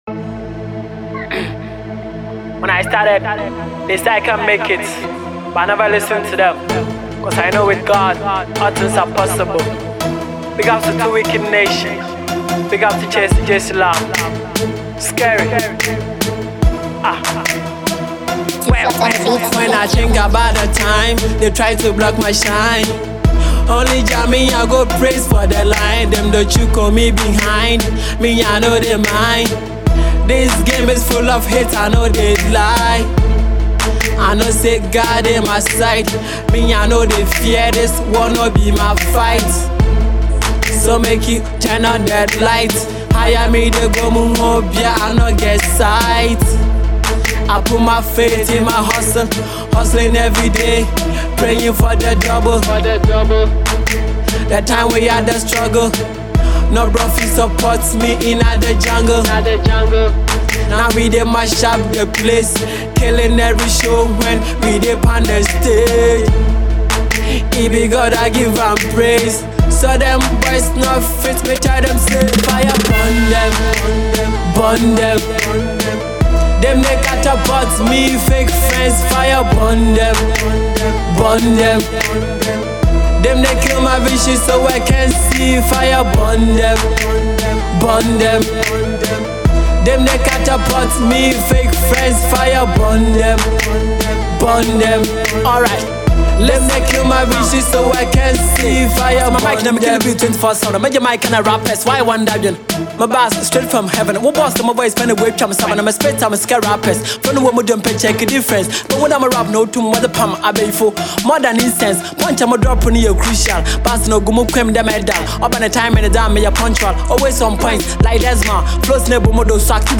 rapper
Hip-Hop